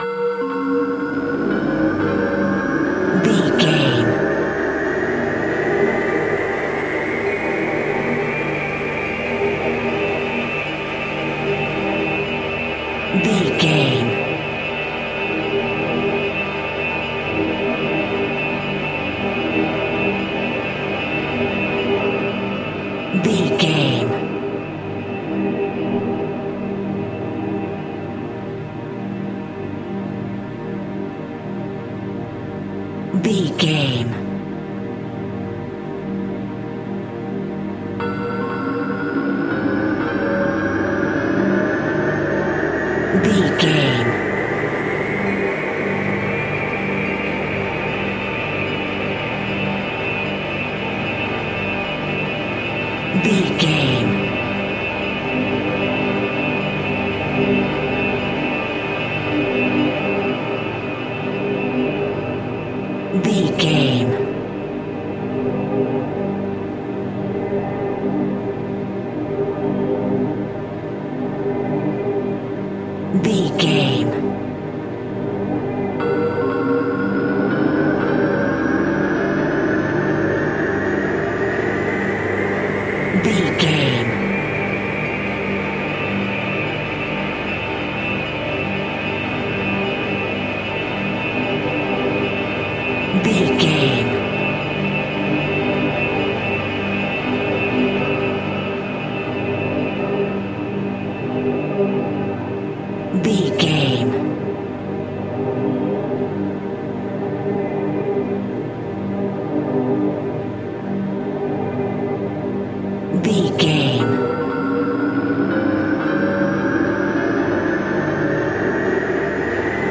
Atonal
tension
ominous
haunting
eerie
synths
Synth Pads
atmospheres